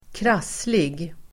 Uttal: [²kr'as:lig]